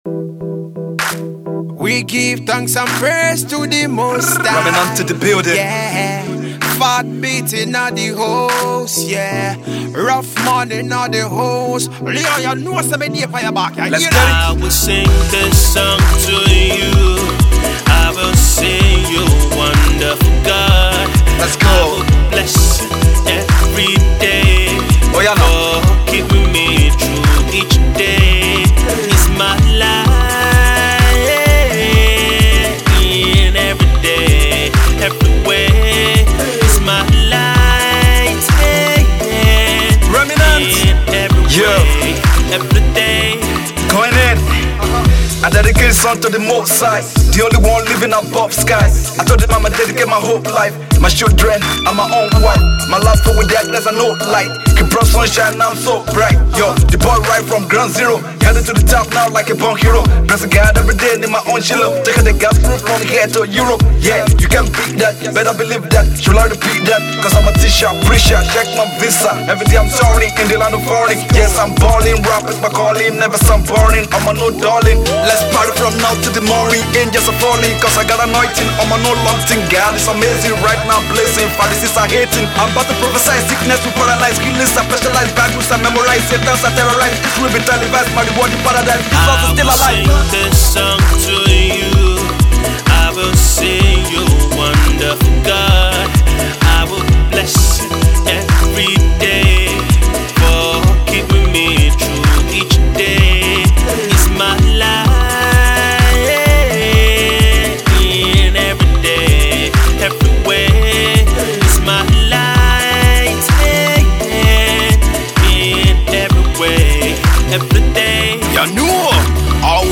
Rapper
a blend of Hip Hop, Afro-pop and Reggae sounds